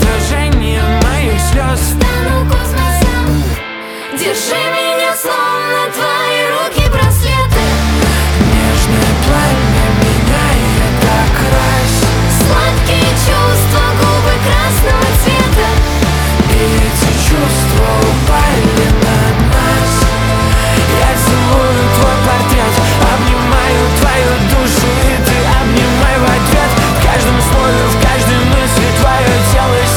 Soundtrack Dance